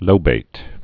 (lōbāt) also lo·bat·ed (-bātĭd)